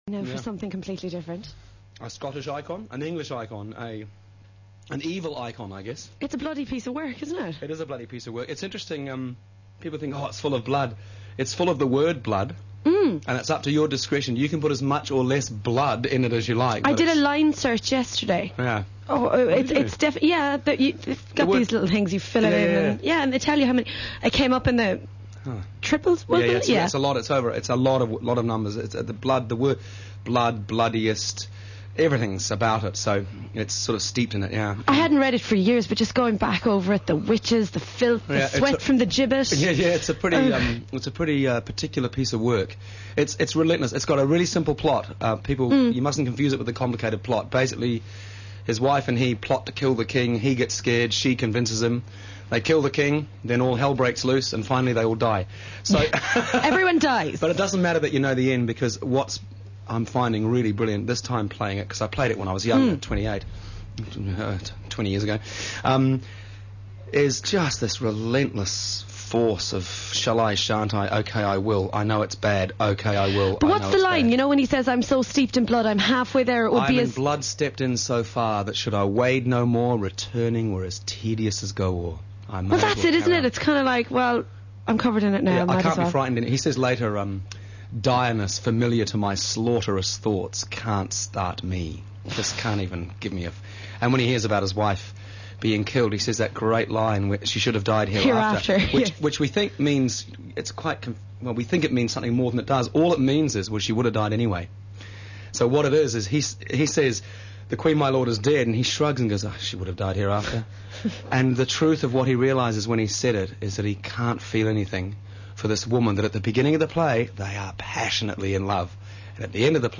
Yay! Site update: radio interview with Michael Hurst
The .mp3 starts a little way into the interview, so the 'Here I am, eating tuna' quote isn't in there, but the rest is loud and clear. Michael does quite a few line readings from MacBeth , discussing his interpretation of them with the interviewer, who is clearly having a ball.
hurstinterview.mp3